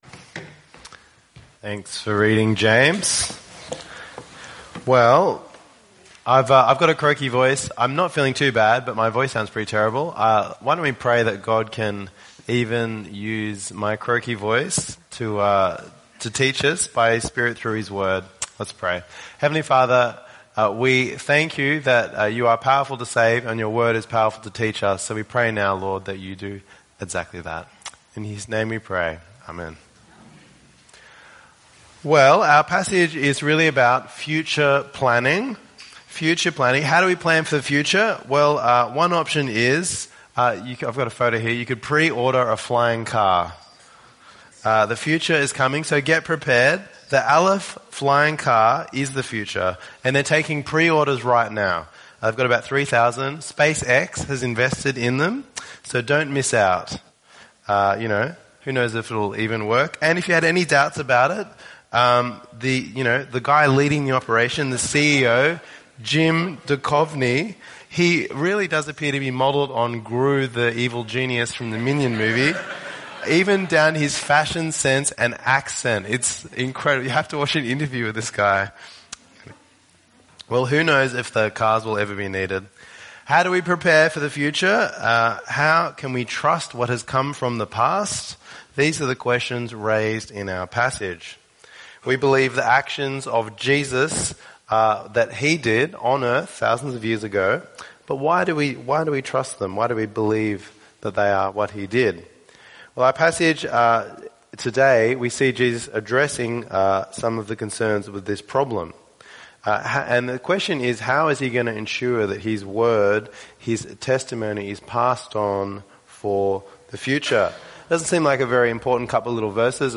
Bexley North Anglican Church Sermon Podcast